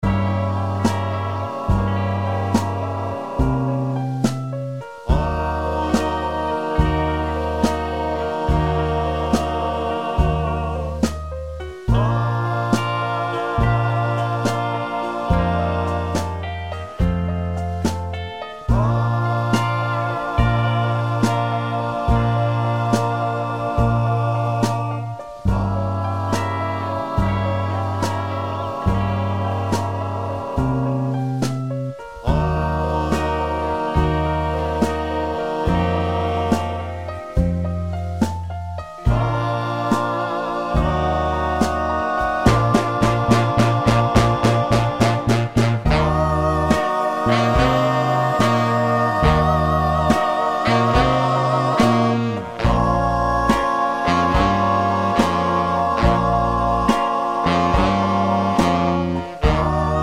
no Backing Vocals Soul / Motown 2:50 Buy £1.50